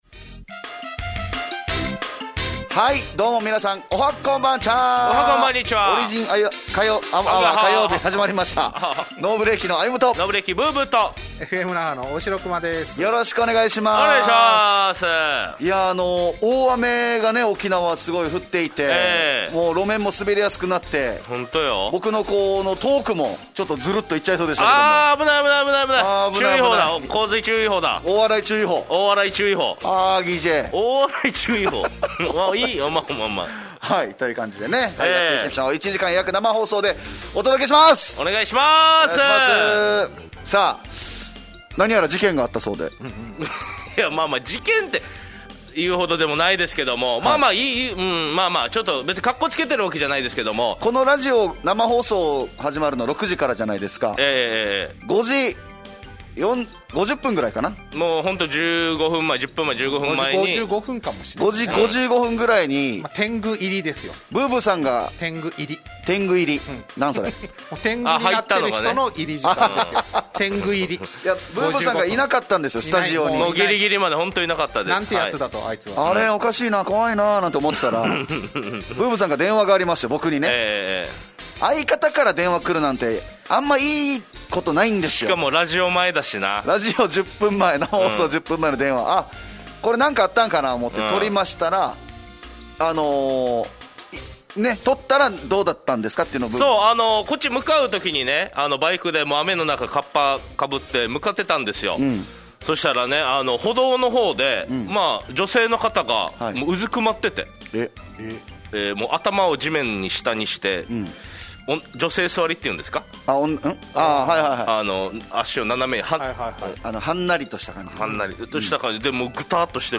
【良音再UP】